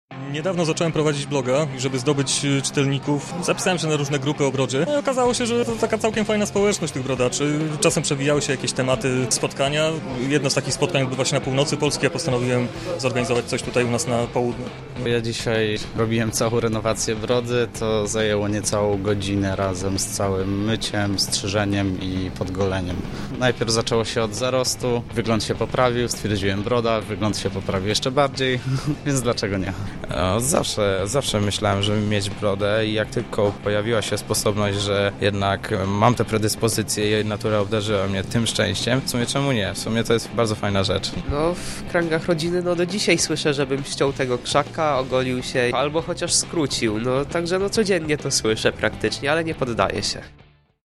W Lublinie odbył się pierwszy zlot brodaczy.
Posłuchajcie o czym dyskutowali między sobą posiadacze brody.